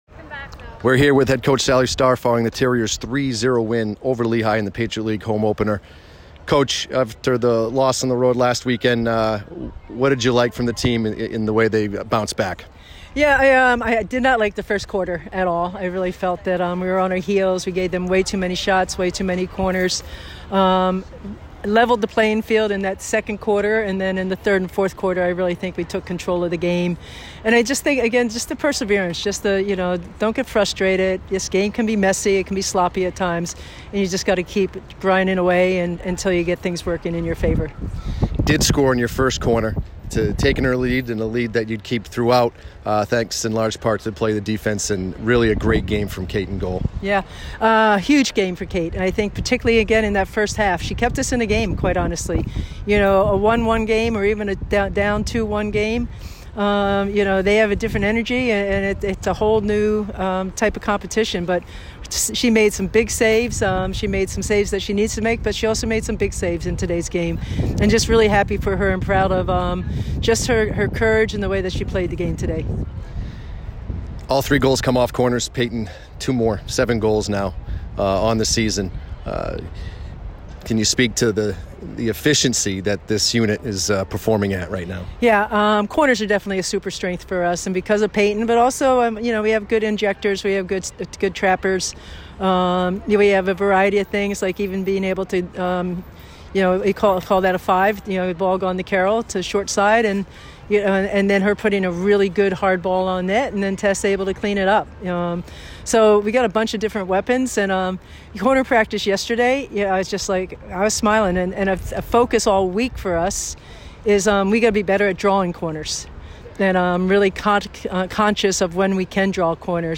Field Hockey / Lehigh Postgame Interview (9-28-24)